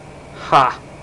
Ha Sound Effect
Download a high-quality ha sound effect.